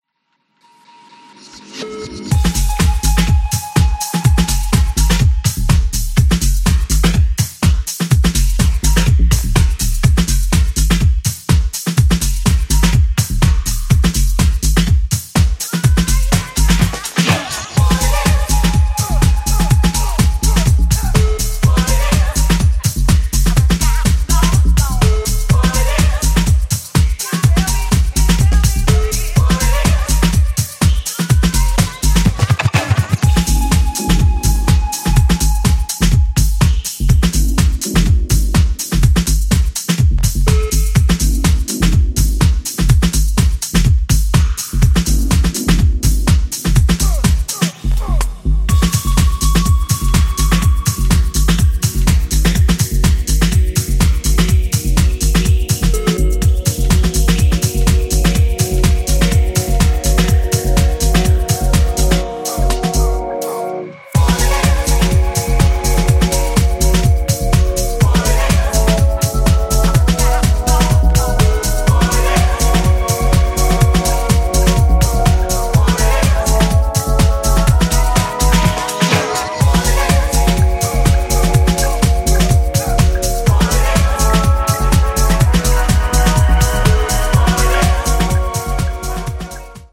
ジャンル(スタイル) HOUSE / CROSSOVER